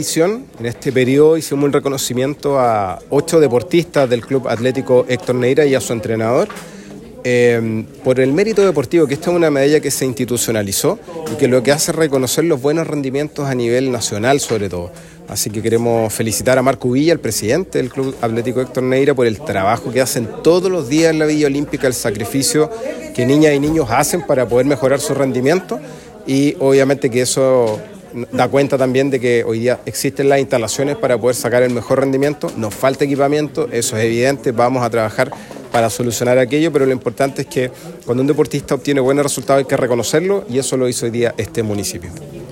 El concejal Miguel Arredondo enfatizó en la importancia de seguir impulsando el desarrollo del deporte en Osorno, destacando la necesidad de proporcionar mejores herramientas e infraestructura para que los deportistas locales continúen creciendo.